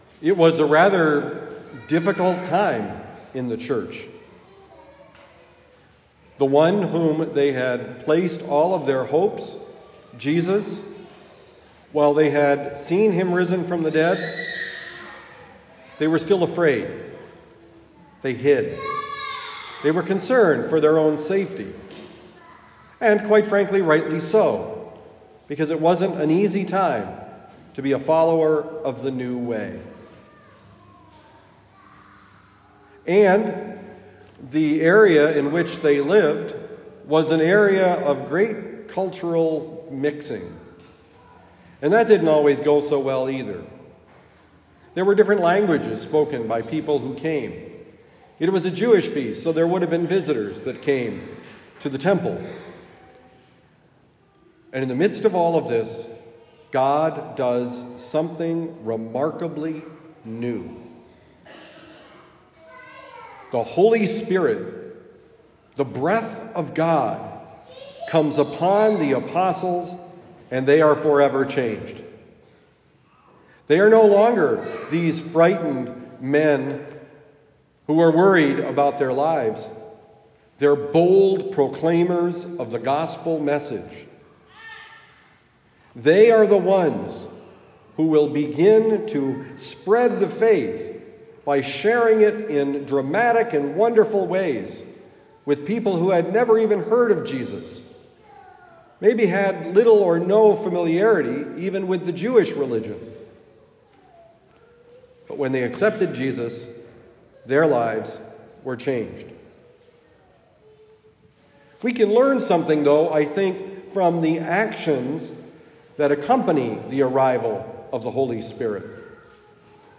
The Holy Spirit Wins: Homily for Sunday, May 28, 2023
Given at Our Lady of Lourdes Parish, University City, Missouri.